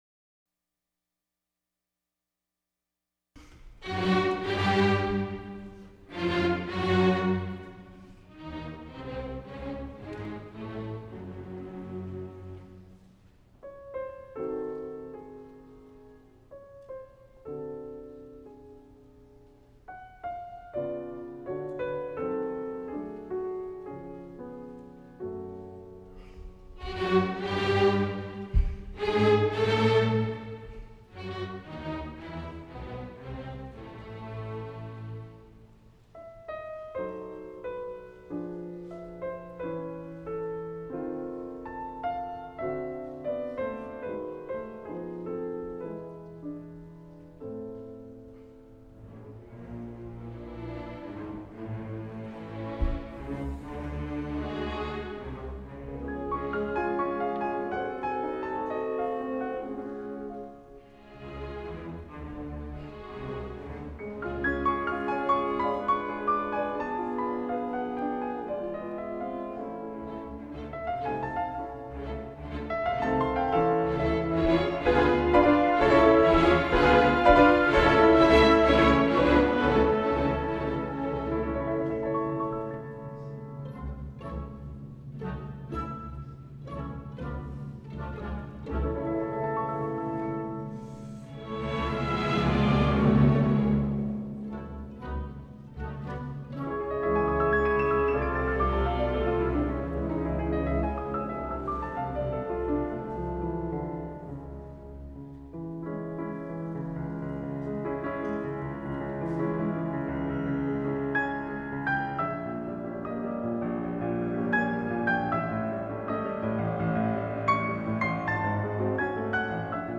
Aspen Music Festival
Concert Pianist